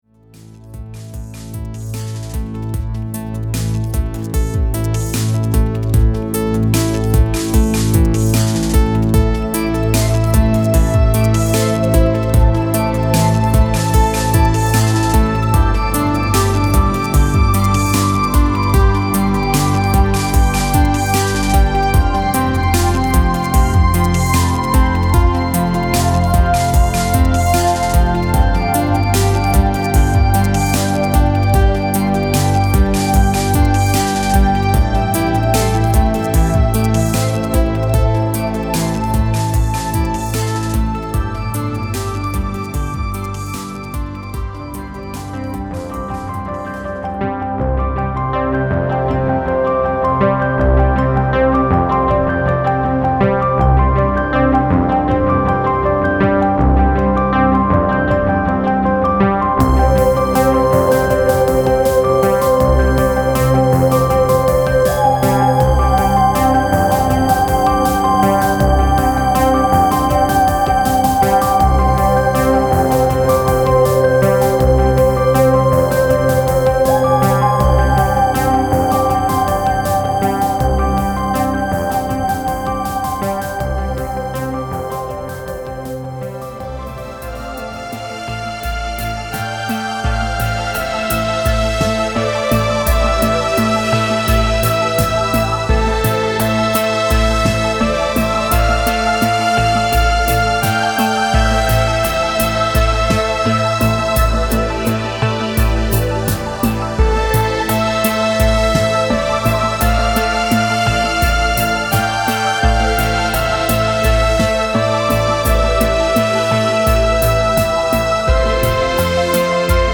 file under: Electronic Music, NewAge
album with very catchy and playful melodies
The result is always rousing and fresh as on the first day.